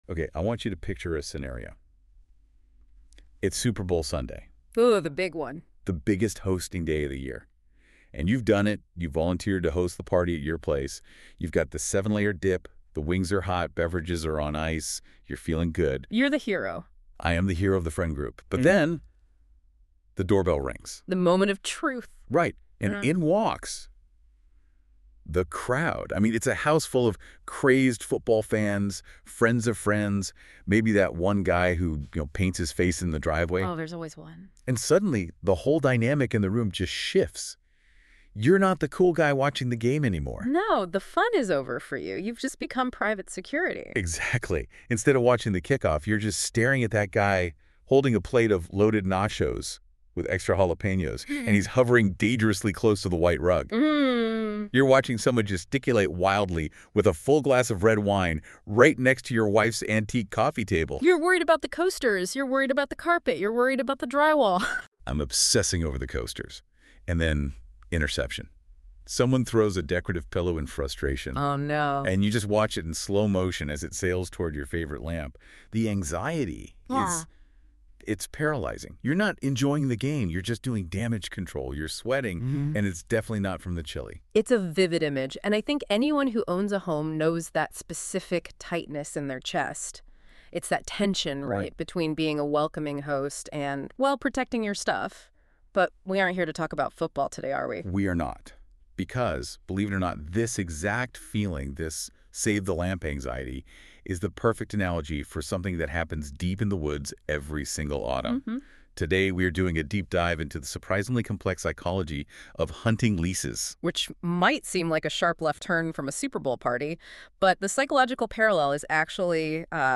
AI generated summary Hunting lease insurance is essential for maintaining positive landowner relationships. By providing liability coverage for accidents and injuries, it reduces tension and prevents intrusive oversight.